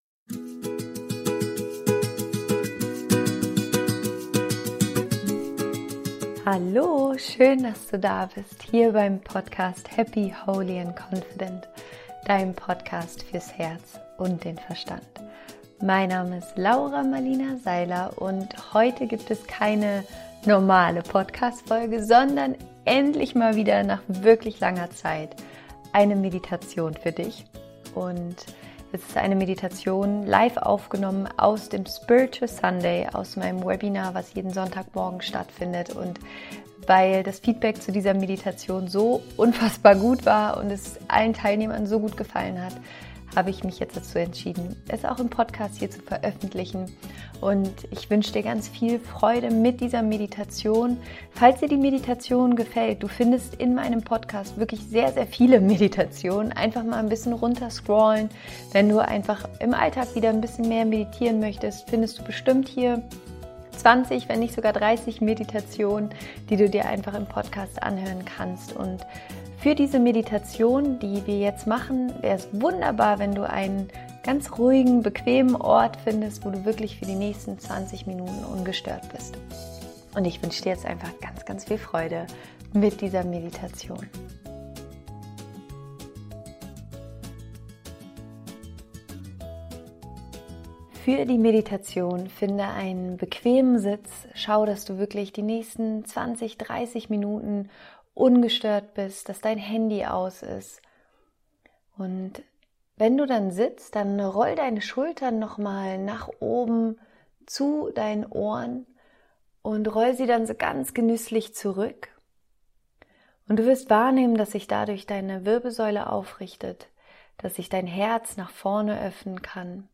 Geleitete Meditation: Verbinde dich mit deinem Krafttier
Die Meditation ist eine Live Aufzeichnung vom Spiritual Sunday und sie hilft dir dabei, dich mit einer tiefen Quelle der Weisheit und deinem spirituellen Krafttier zu verbinden.